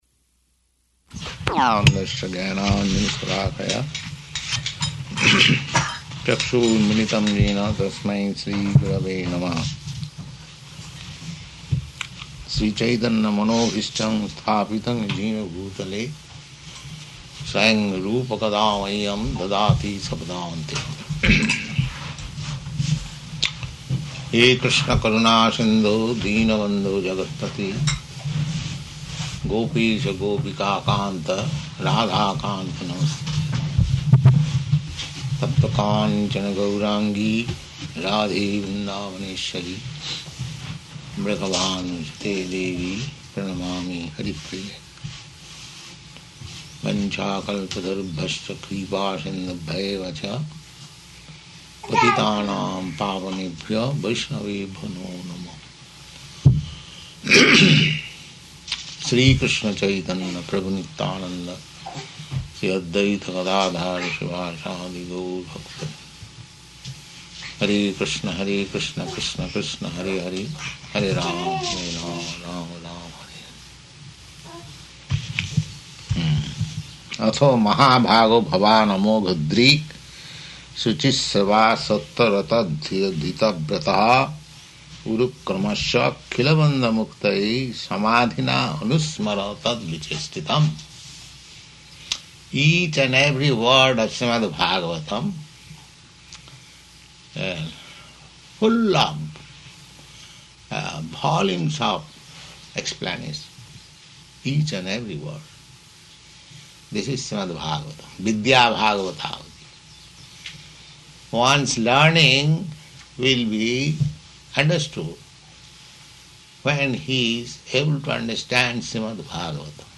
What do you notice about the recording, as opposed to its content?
Location: New Vrindavan